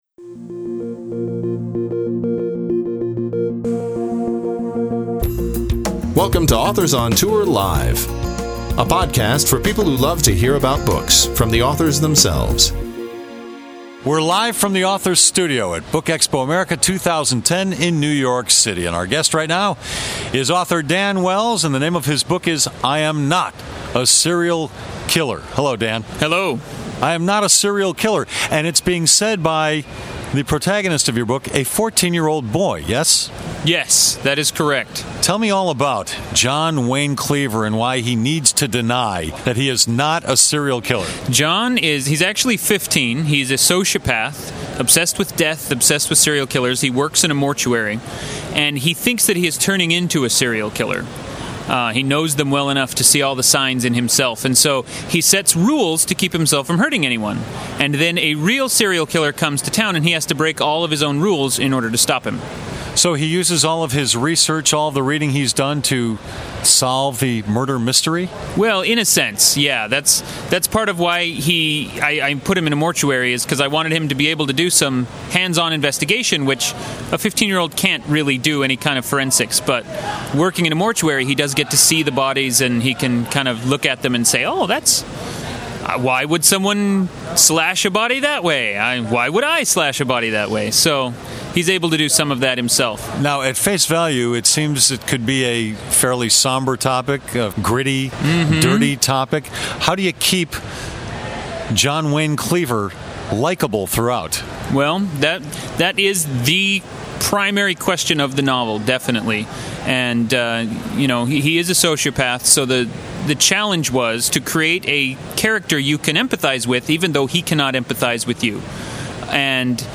Download This podcast was recorded at BookExpo America 2010 in New York City.